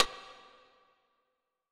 Metro Rimshot (WET) .wav